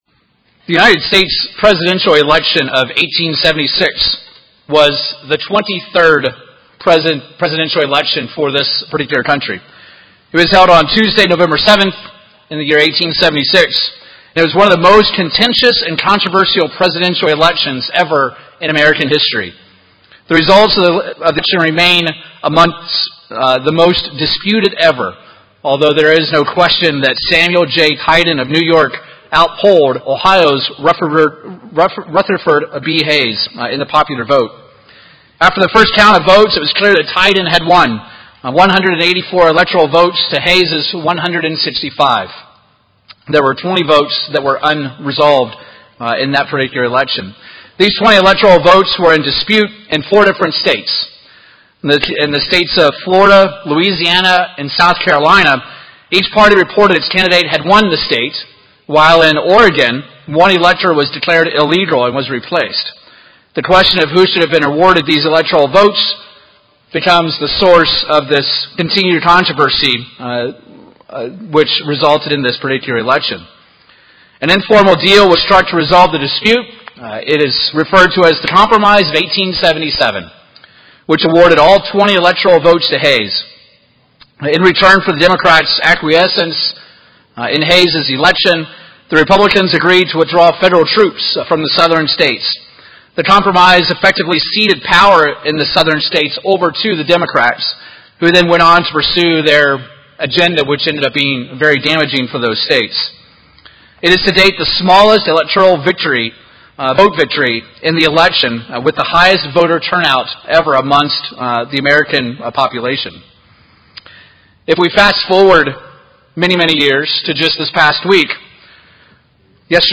In this sermon we look to the Bible for the ultimate candidate and platform.
Given in Oklahoma City, OK